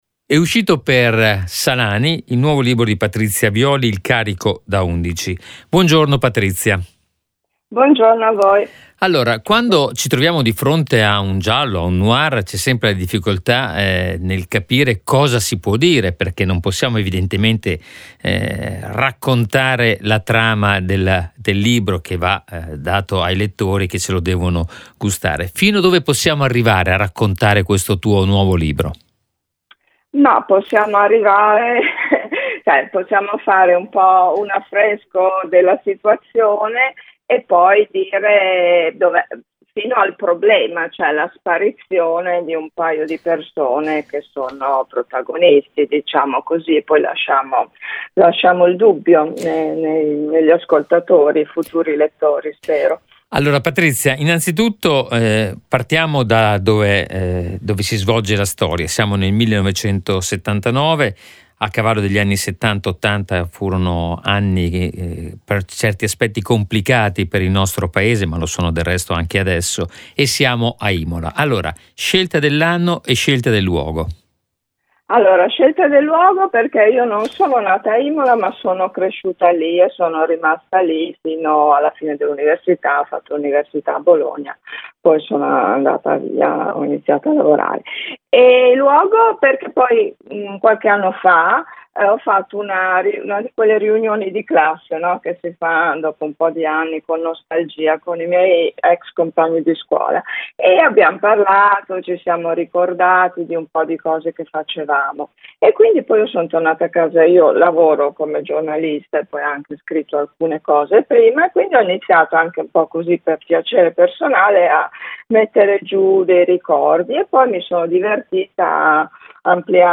che abbiamo raggiunto al telefono.
L’intervista